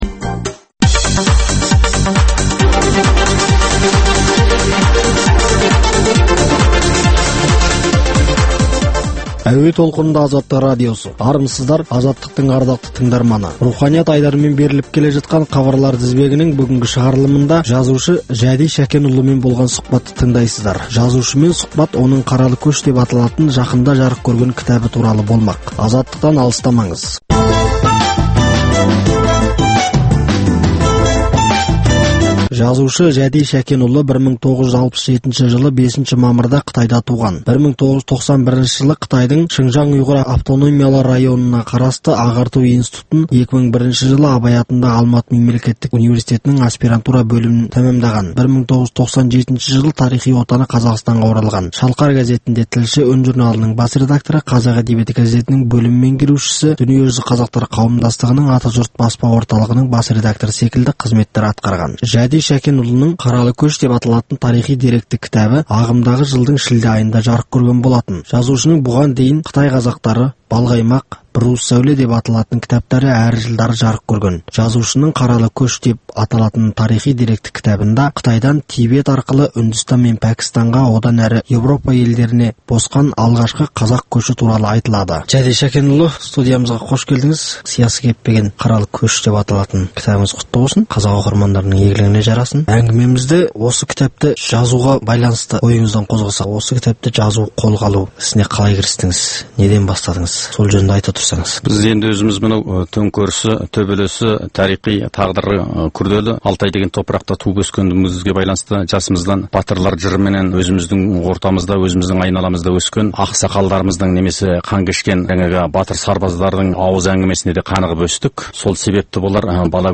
сұхбат